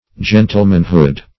Search Result for " gentlemanhood" : The Collaborative International Dictionary of English v.0.48: Gentlemanhood \Gen"tle*man*hood\, n. The qualities or condition of a gentleman.
gentlemanhood.mp3